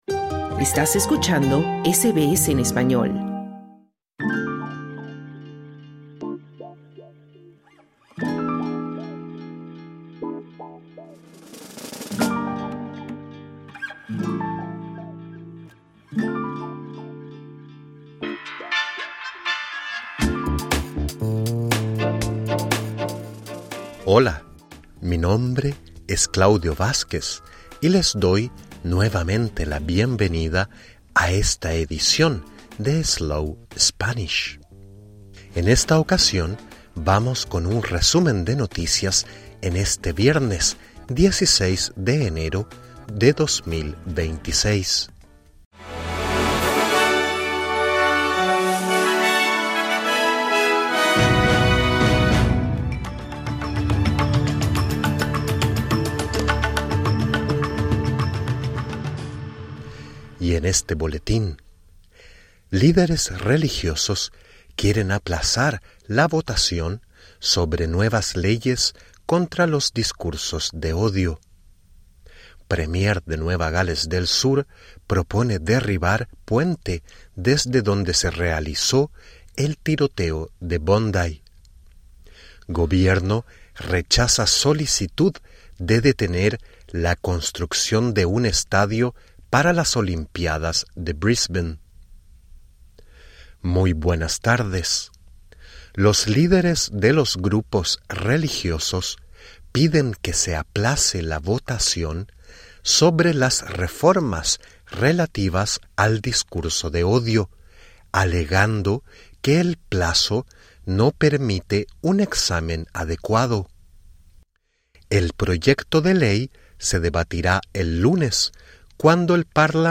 Welcome to SBS Slow Spanish, a new podcast designed in Australia specifically for those interested in learning the second most spoken language in the world. This is our weekly news flash in Spanish for January 16, 2026.